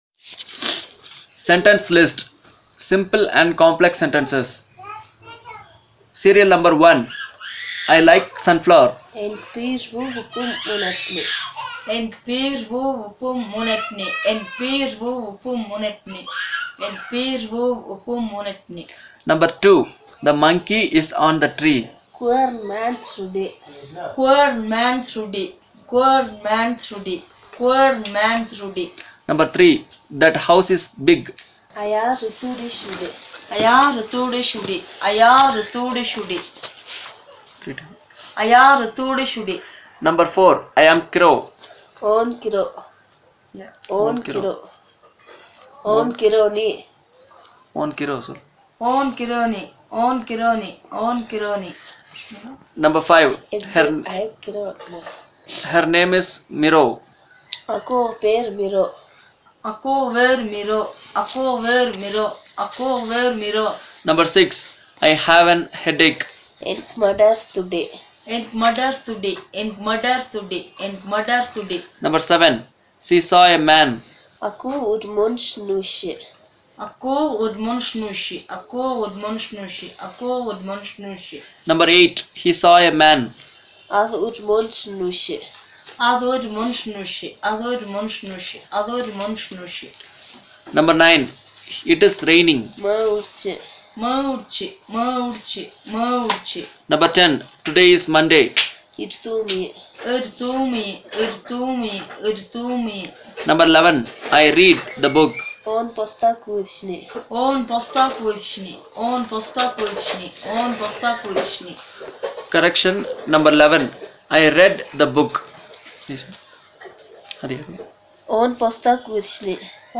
Elicitation of simple and complex sentences, word order and case